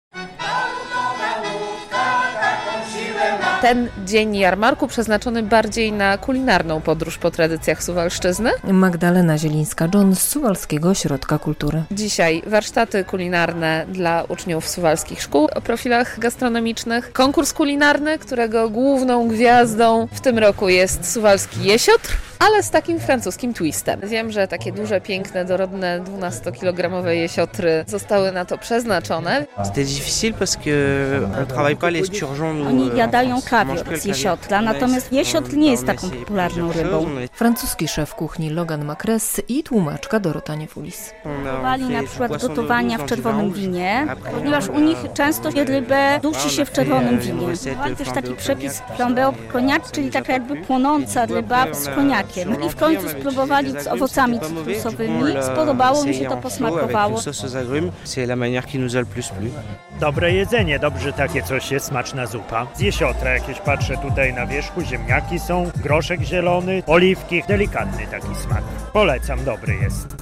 W ostatnim dniu tej imprezy na Placu Konstytucji 3 maja królował lokalny jesiotr w wersji francuskiej.